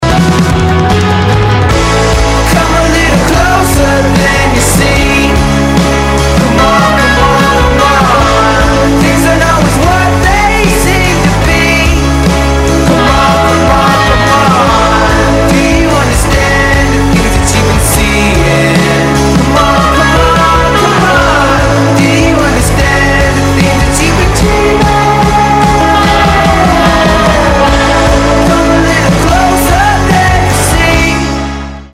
рок группы